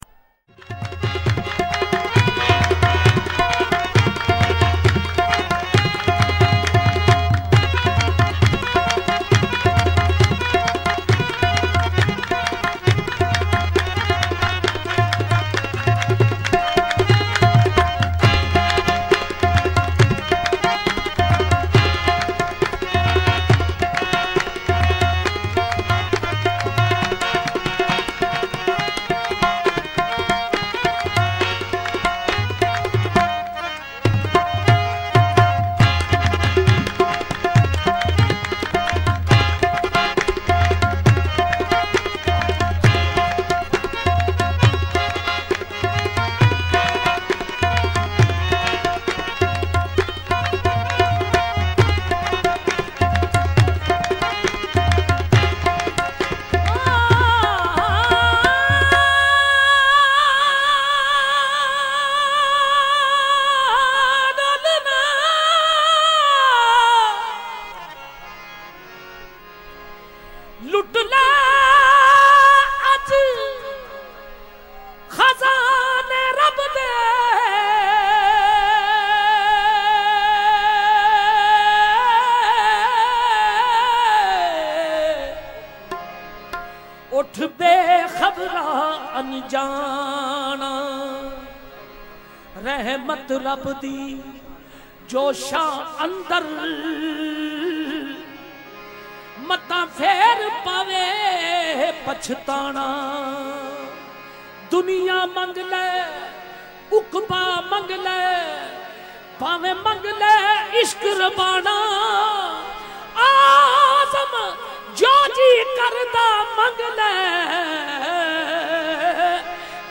Qawwali